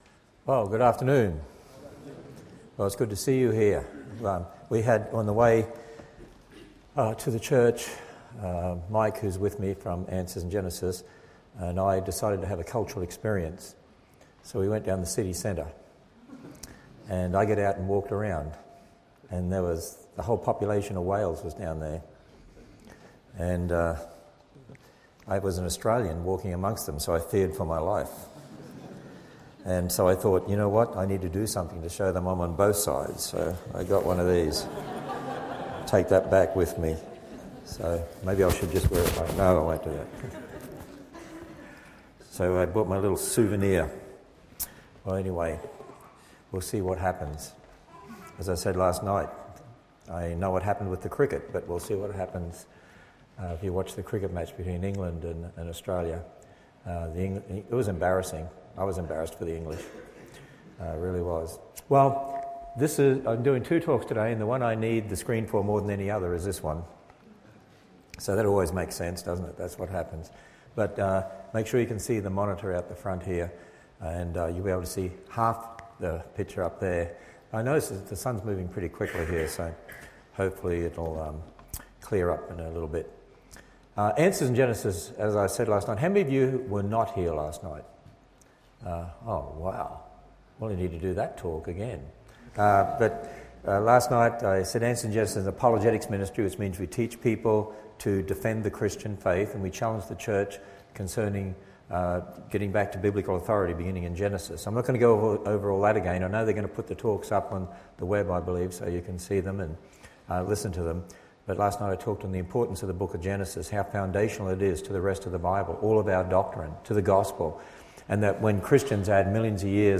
Service Type: Special Meeting